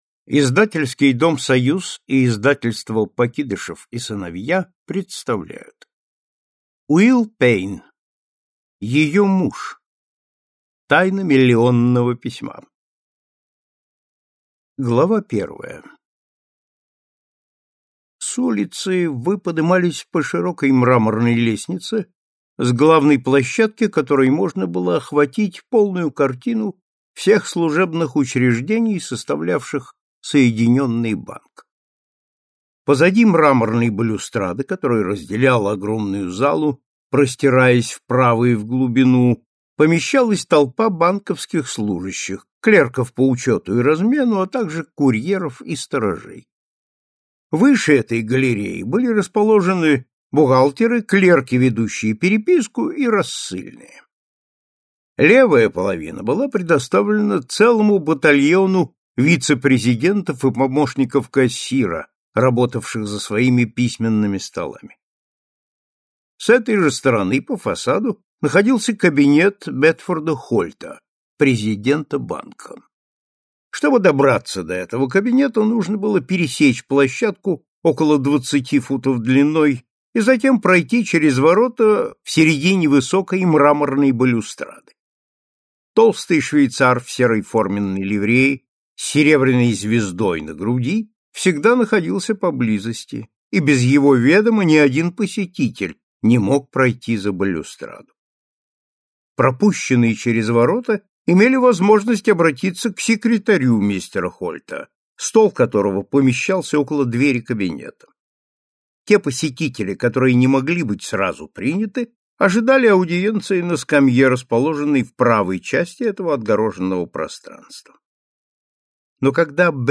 Аудиокнига Ее муж. Месть сыщика | Библиотека аудиокниг